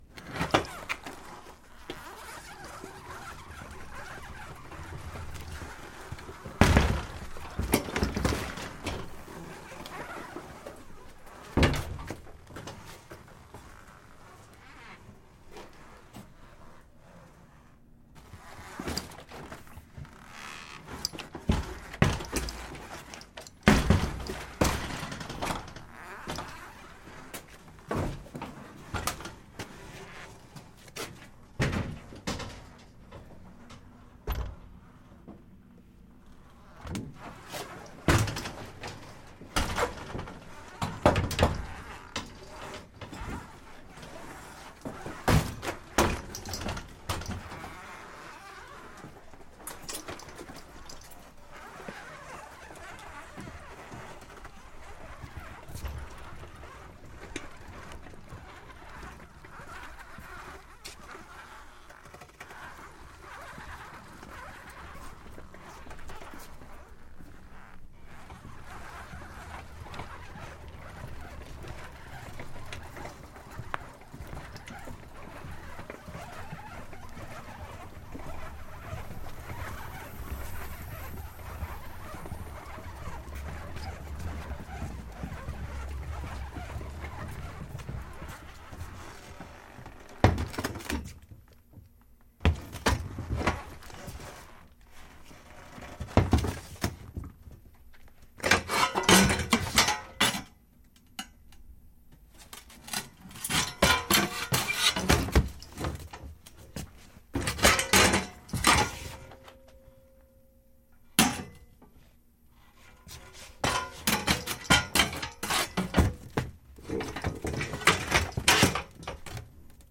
随机" 金属送货车与牛奶箱推过双扇门，进入Depanneur角落商店的店面，在bg周围敲打。
描述：金属交付小车与牛奶箱通过双门进入depanneur角店商店敲打bgsound1.flac